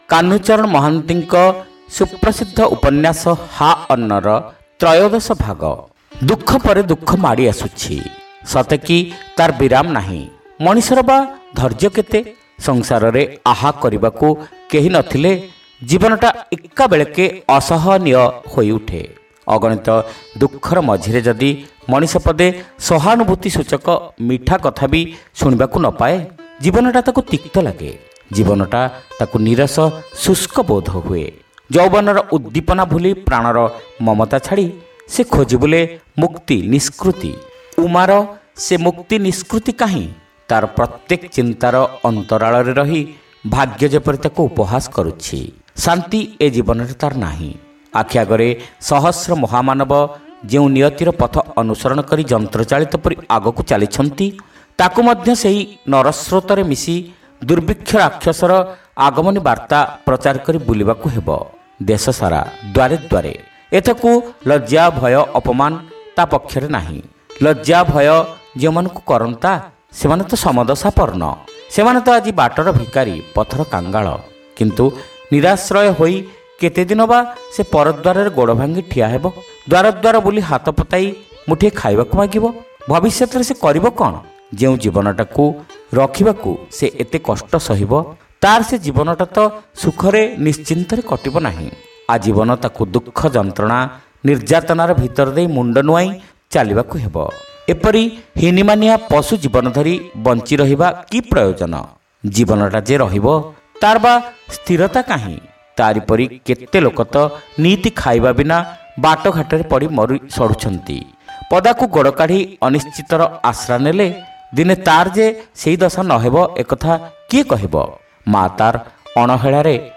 ଶ୍ରାବ୍ୟ ଉପନ୍ୟାସ : ହା ଅନ୍ନ (ତ୍ରୟୋଦଶ ଭାଗ)